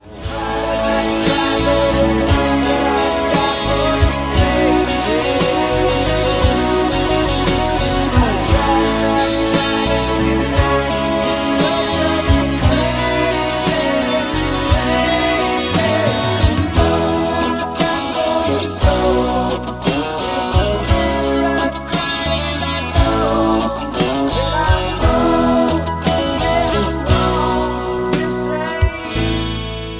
MPEG 1 Layer 3 (Stereo)
Backing track Karaoke
Rock, 1990s